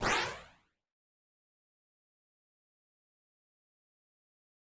flame2.mp3